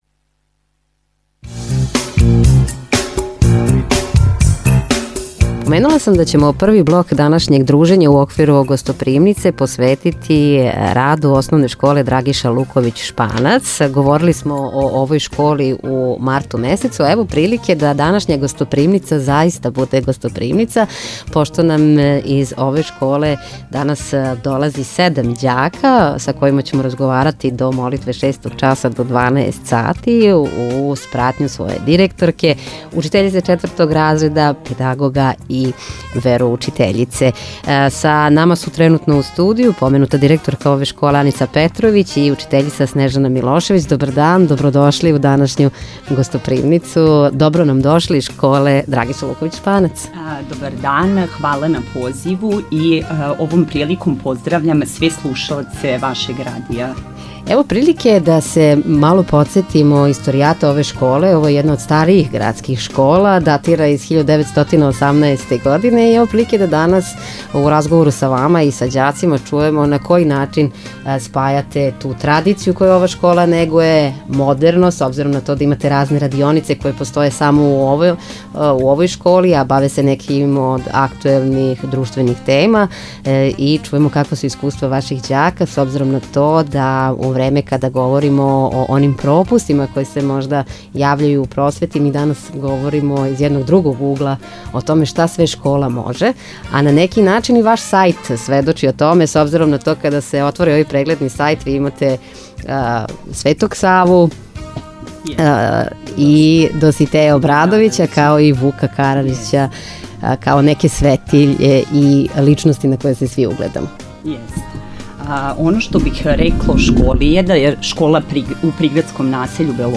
У данашњем издању емисије „Гостопримница“ угостили смо представнике ОШ „Драгиша Луковић Шпанац“. Школа се налази на магистралном путу Крагујевац – Јагодина, у насељу Белошевац.